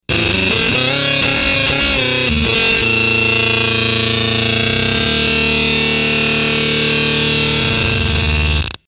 Citation : pfruuuuuuitttt fraaaaat skuiiiiiizzzzz abelelelelele
n-riff1.mp3